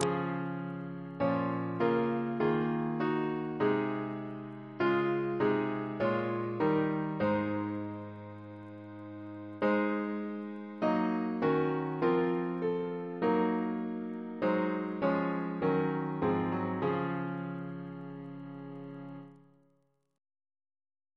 Double chant in D♭ Composer: Ethel Hodgson Reference psalters: ACB: 323